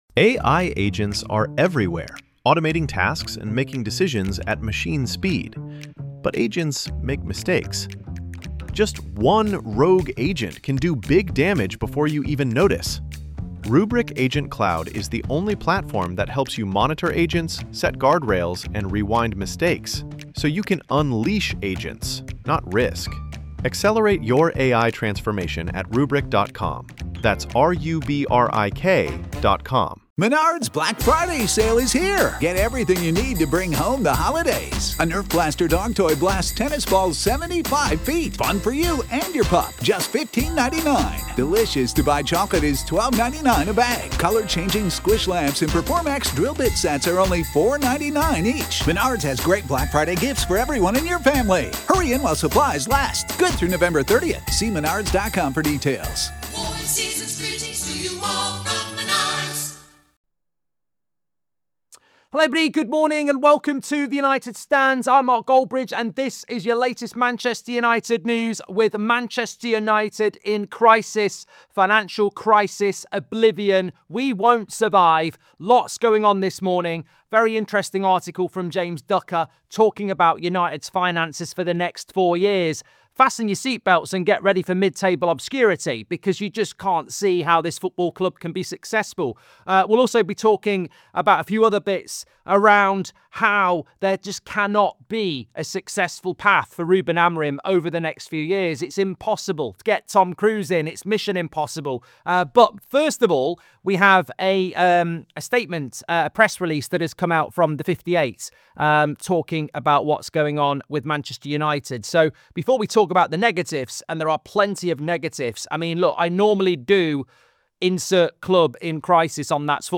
4/3/2025 - Morning News! Manchester United are facing financial ruin and Ruben Amorim has no chance of success as fan groups unite to disrupt the owners.